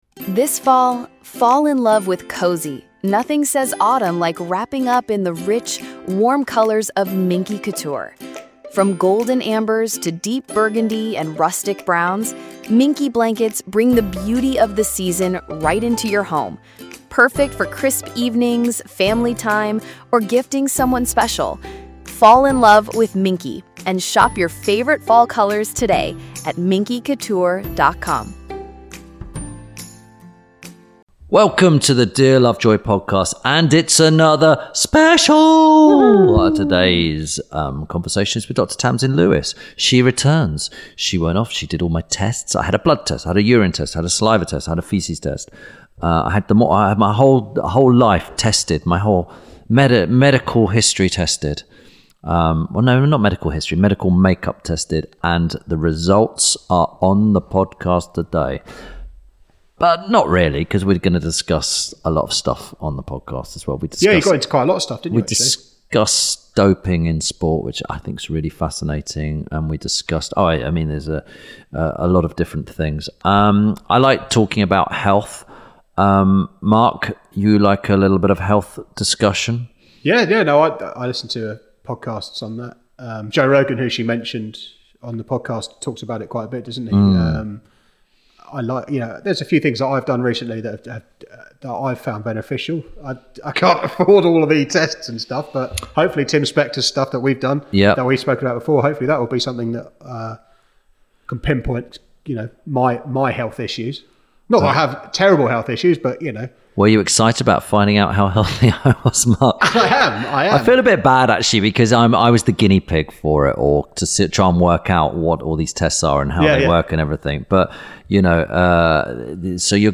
– INTERVIEW SPECIAL